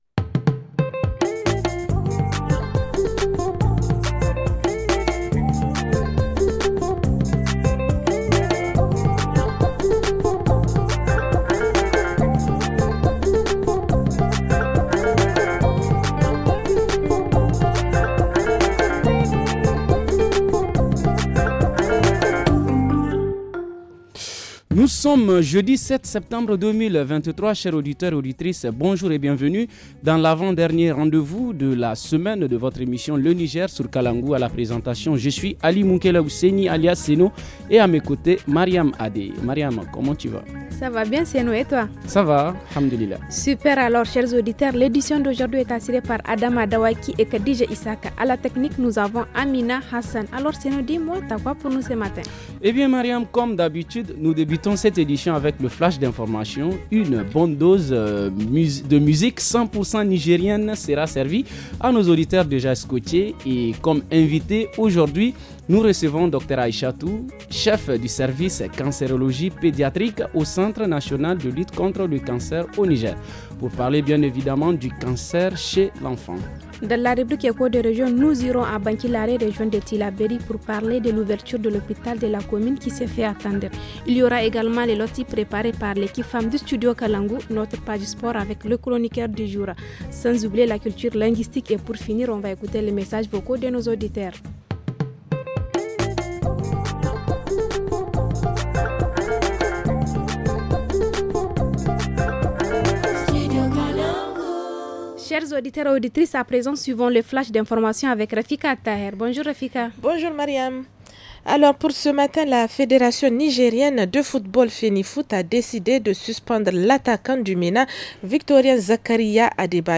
-Reportage Région : L’hôpital de Bankilaré non fonctionnel ;